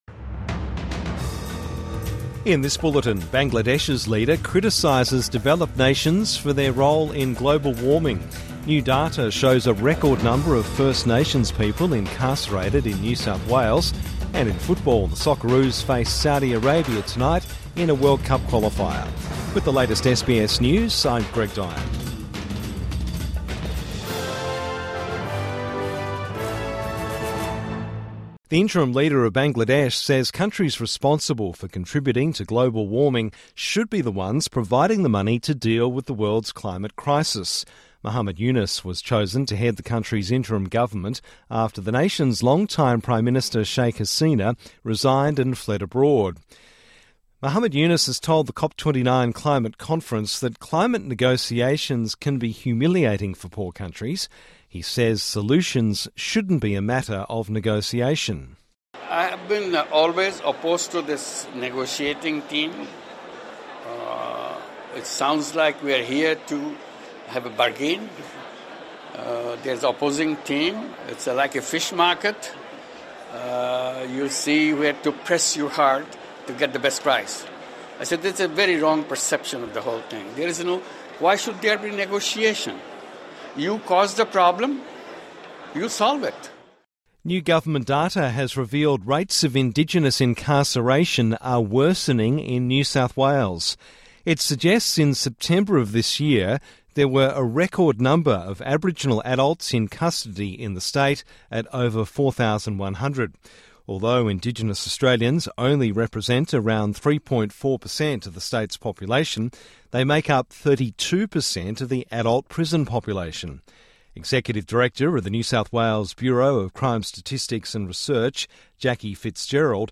Morning News Bulletin 14 November 2024